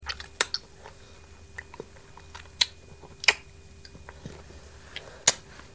babyeat.ogg